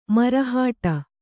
♪ marahāṭa